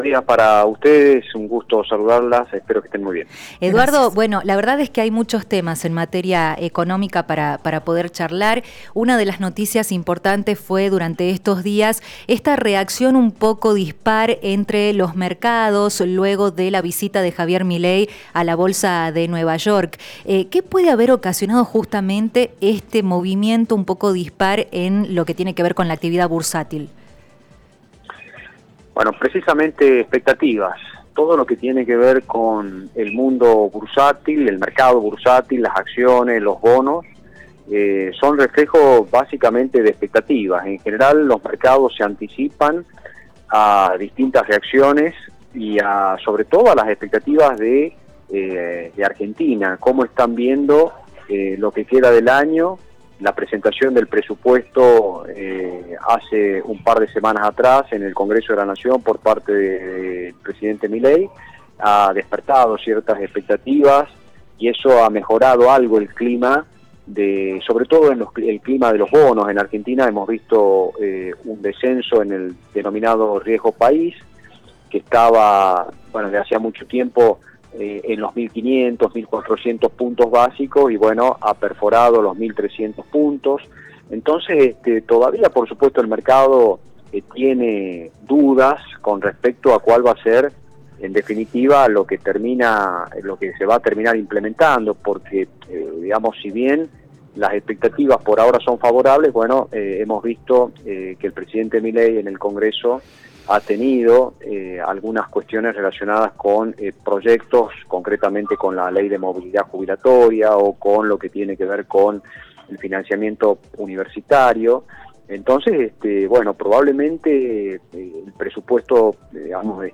en diálogo con Café Prensa analizó la respuesta de los mercados respecto a la visita de Javier Milei a Wall Street.